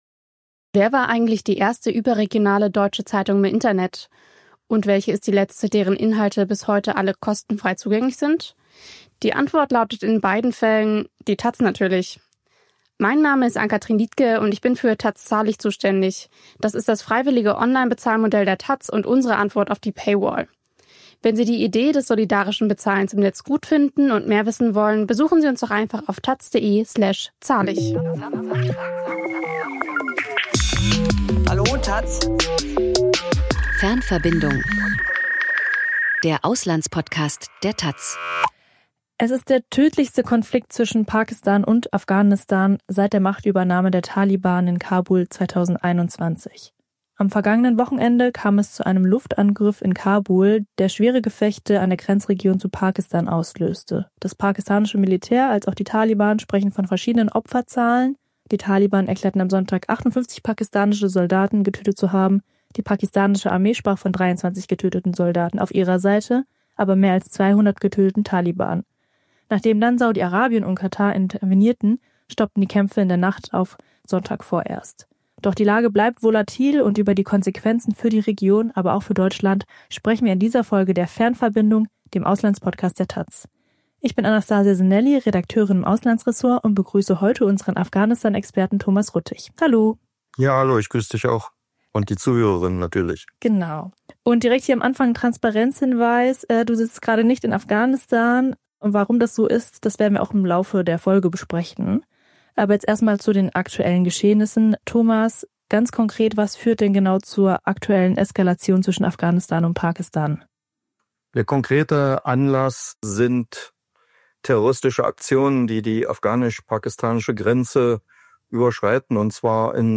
Diese Folge wurde aufgezeichnet am 14. Oktober um 16 Uhr mitteleuropäischer Zeit.